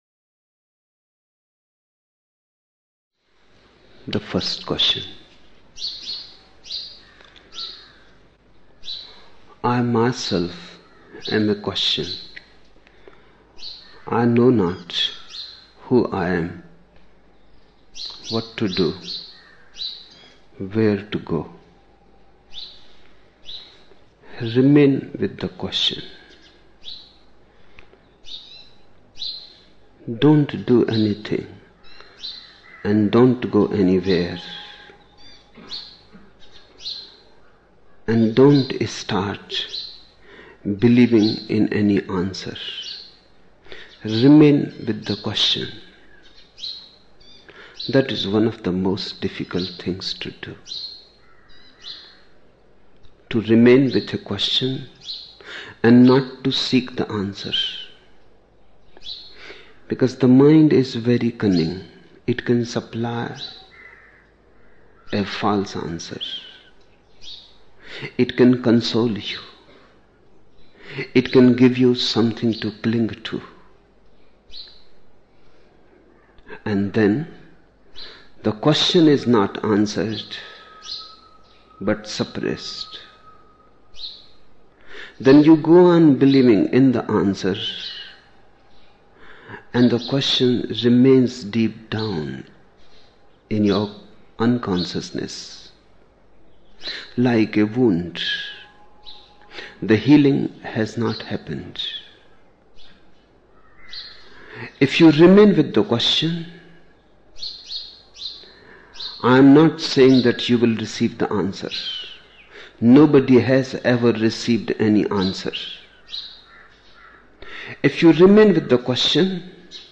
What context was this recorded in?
16 December 1975 morning in Buddha Hall, Poona, India